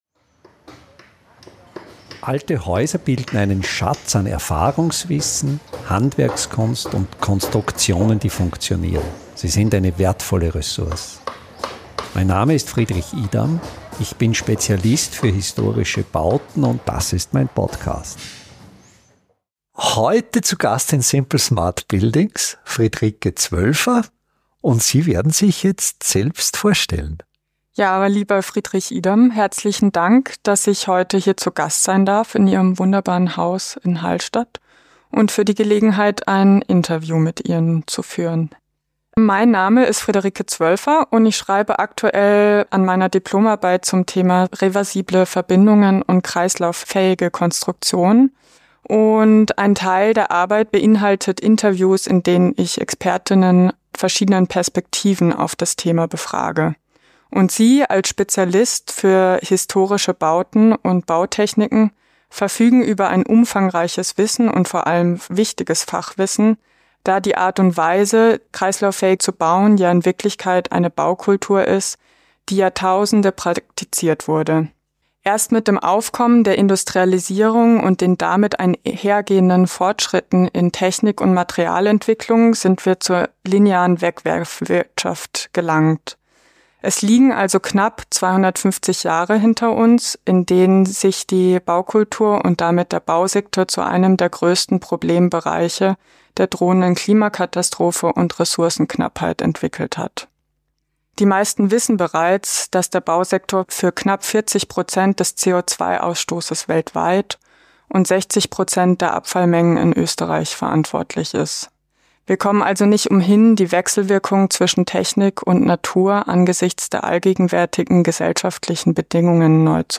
Entscheidend ist dabei die technische Ausführung von Verbindungen, die einerseits lange haltbar und dennoch - bei Bedarf – unkompliziert trennbar sein müssen. Vortrag zur Kulturgeschichte der Wiederverwendung